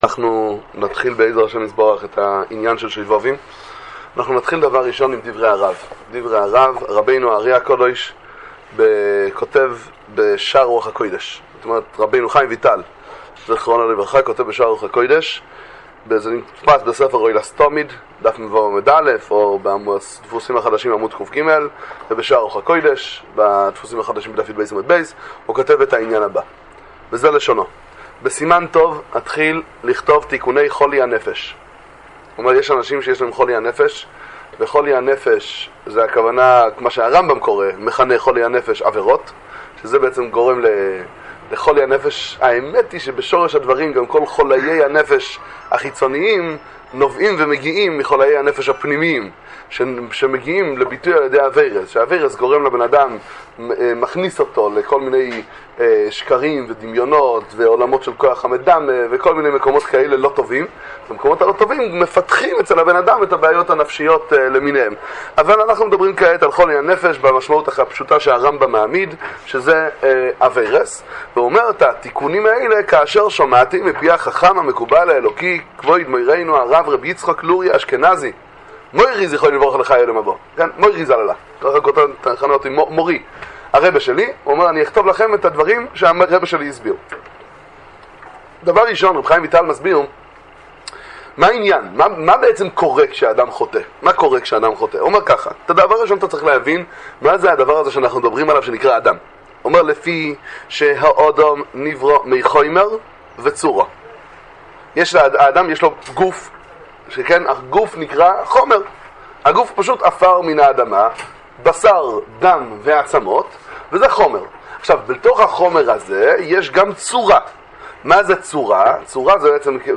שיעור תורה לימי השובבי"ם